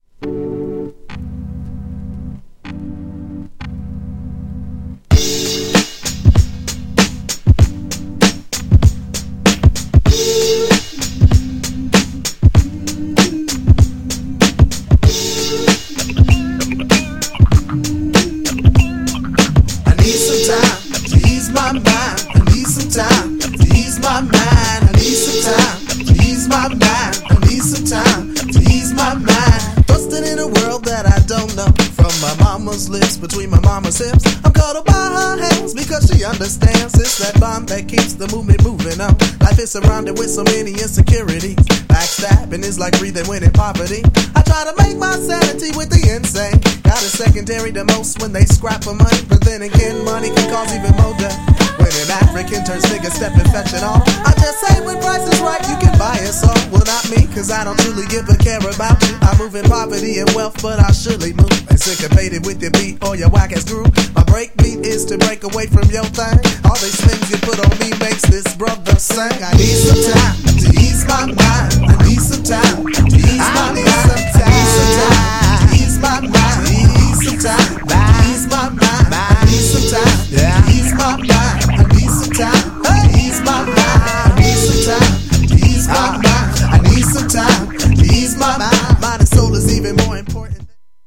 GENRE Hip Hop
BPM 96〜100BPM
SMOOTH_HIPHOP # SMOOTHなフロウ
トライバル